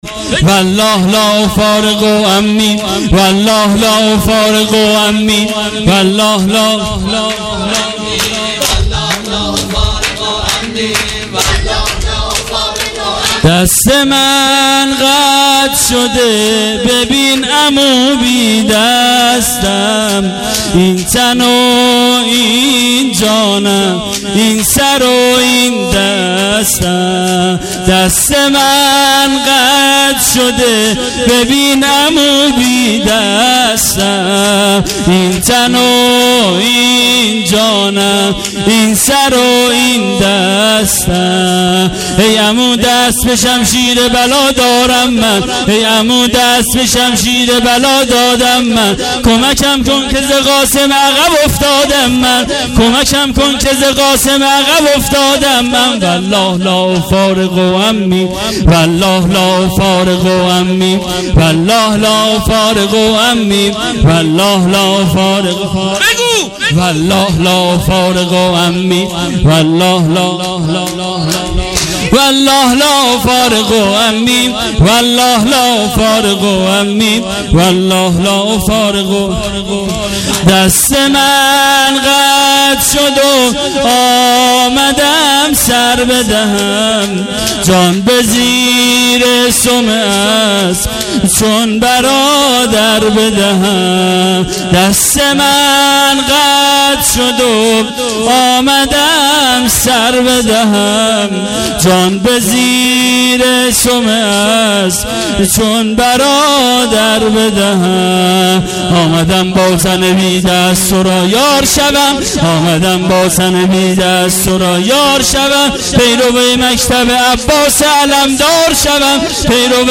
هیئت عاشورا قم